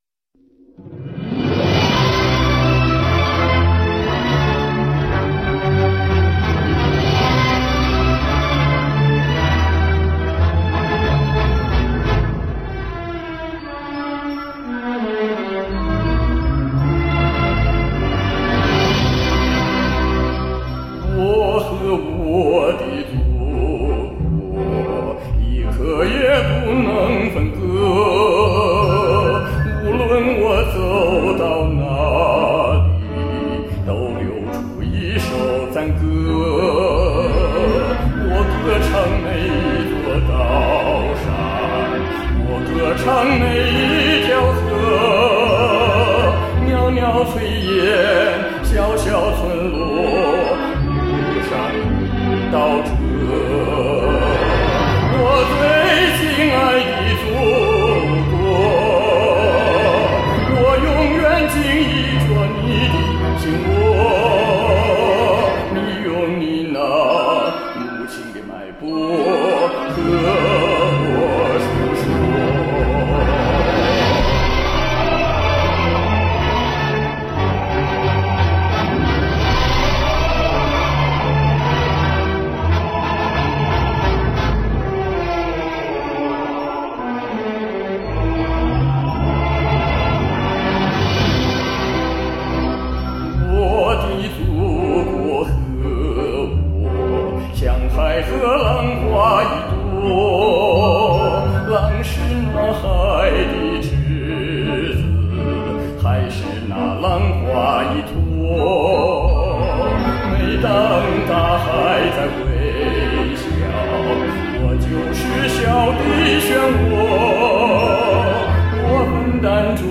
男中音唱这首歌，难度很大。